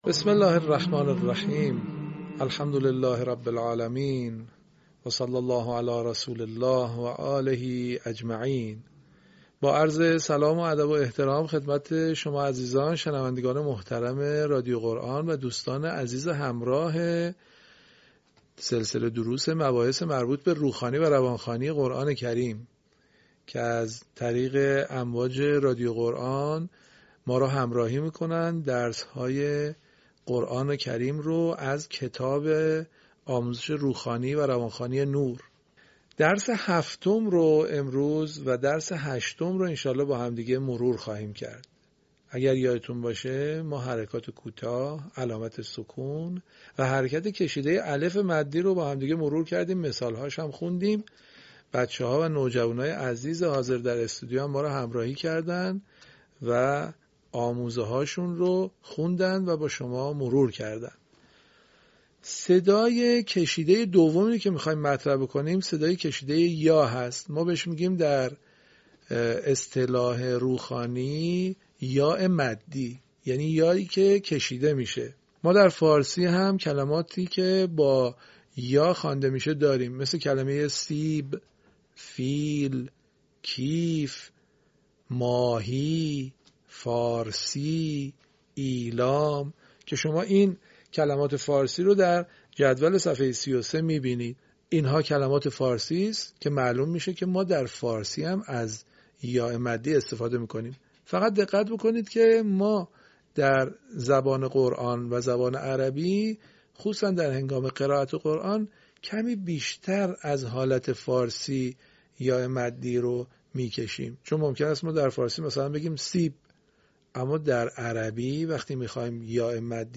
صوت | آموزش «یای مدی» در روخوانی و روانخوانی قرآن